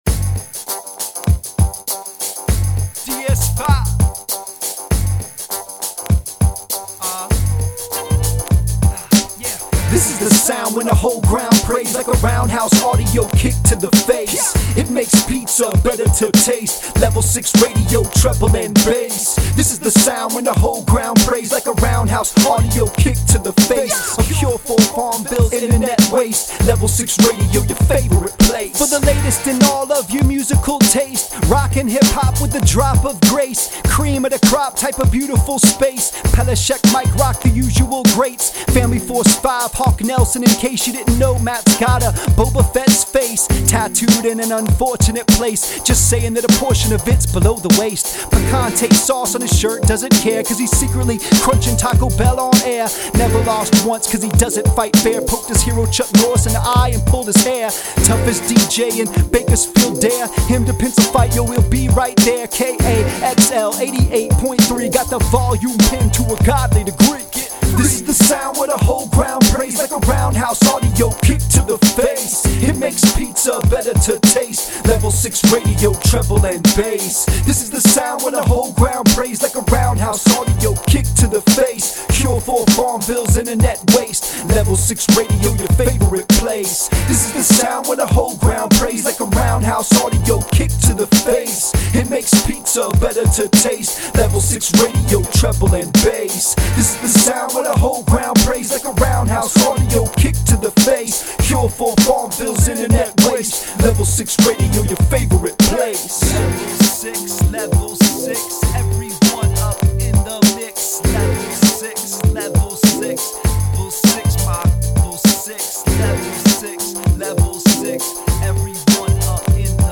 I did this bumper song for the program
The holiday season is getting hectic, but I promise more news rap soon.